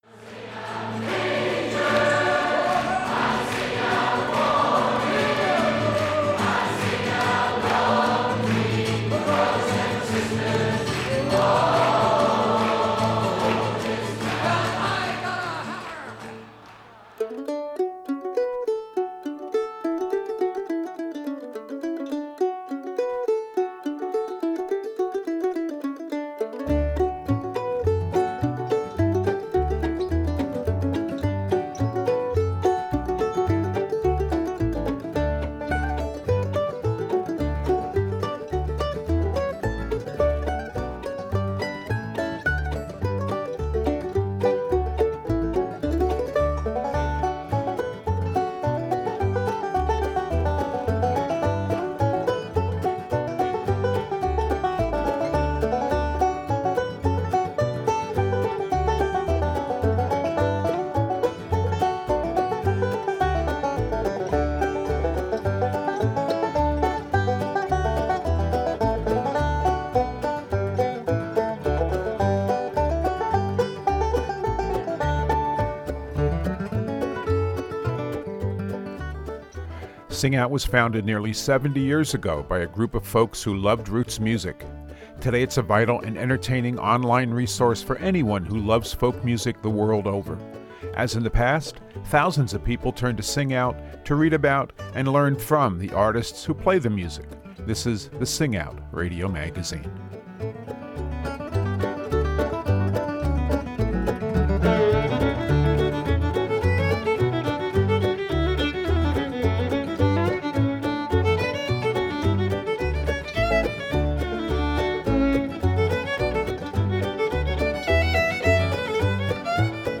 So many musicians toil for years in relative obscurity until their opportunities arrive – not many are truly overnight sensations. This week we begin a two-part feature focusing on some songwriters and instrumentalists that many people don't know much about yet.